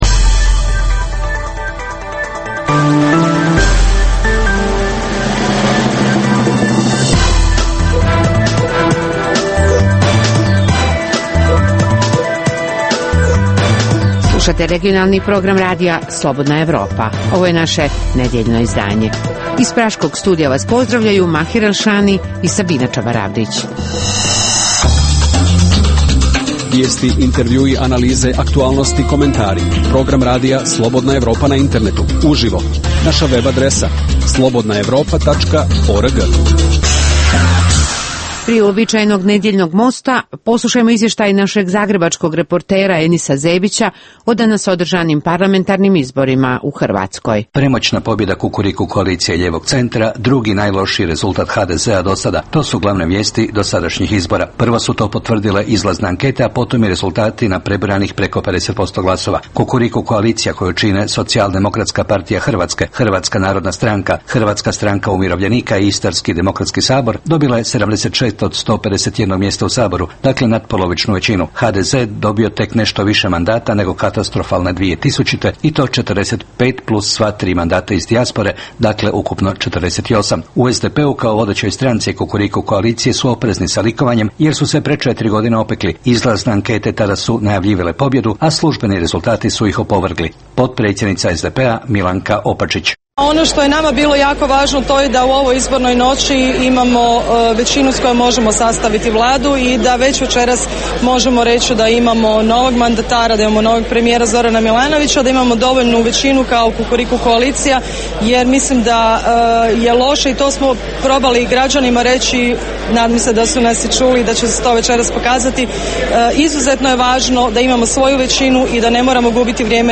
ugledni sagovornici iz regiona diskutuju o aktuelnim temama